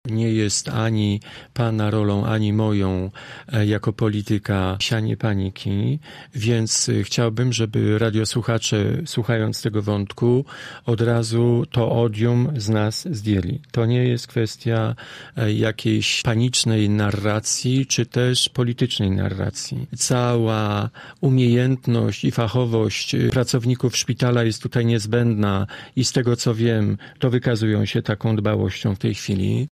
Poseł PiS-u komentuje sytuację epidemiologiczną w Szpitalu Uniwersyteckim.